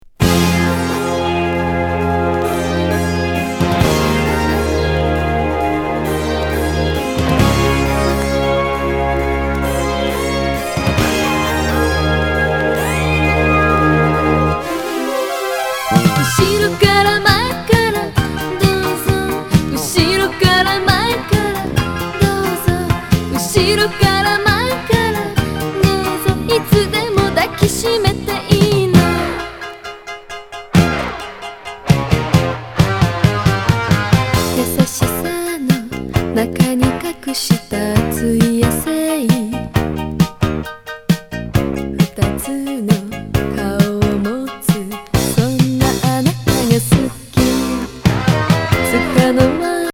18禁ドエロ直球ディスコ歌謡！